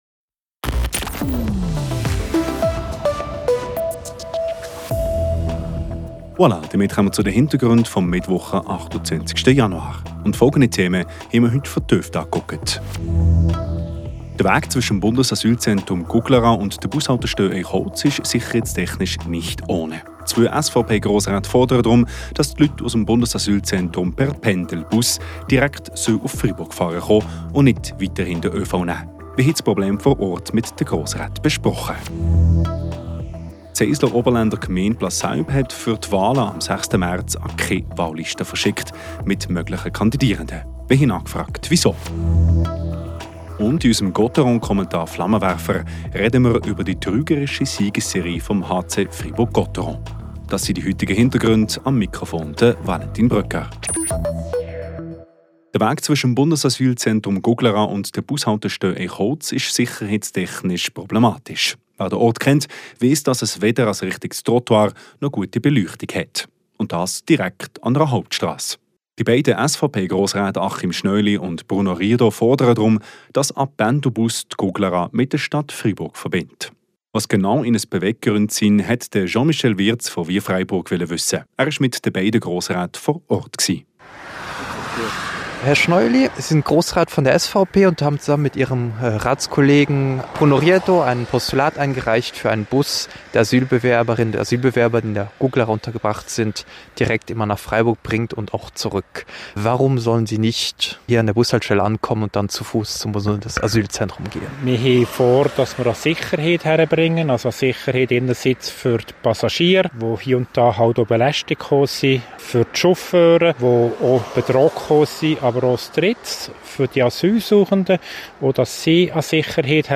Wir waren mit den beiden Grossräten vor Ort. Die Gemeinde Plasselb hat nun doch fünf der sieben Sitze für den Gemeinderat gefunden.